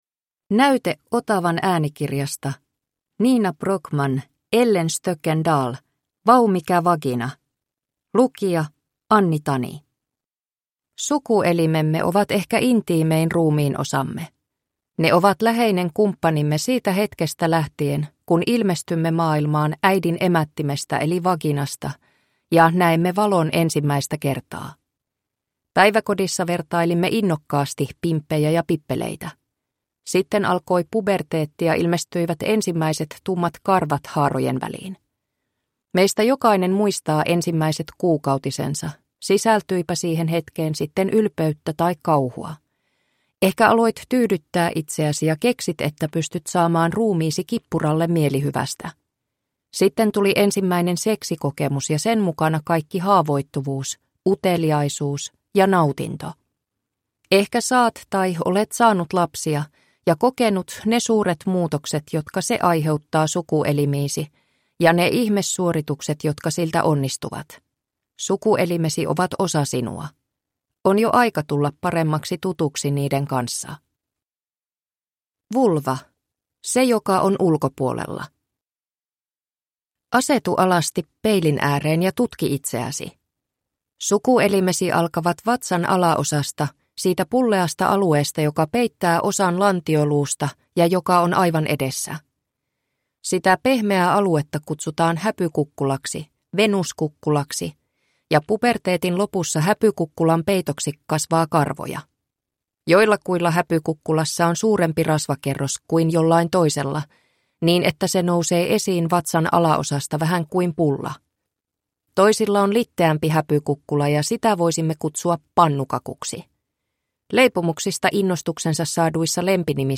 Vau, mikä vagina! – Ljudbok – Laddas ner